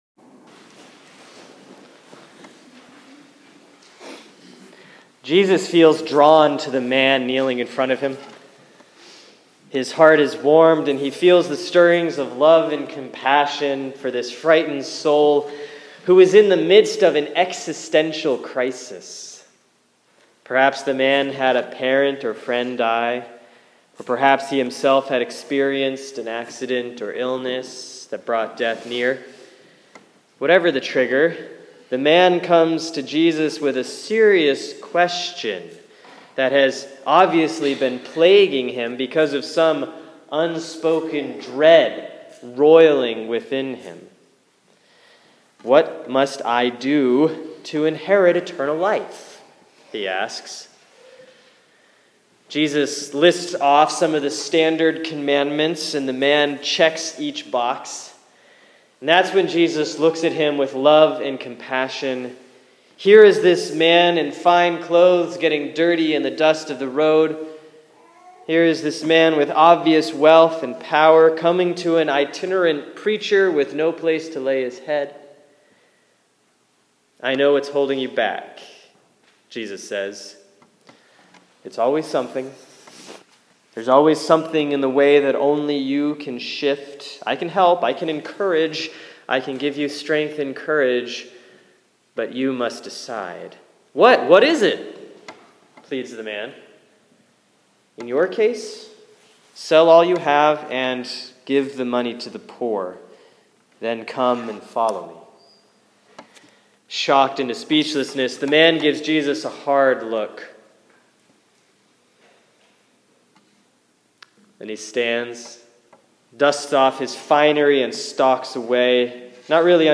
Sermon for Sunday, October 11, 2015 || Proper 23B || Mark 10:17-31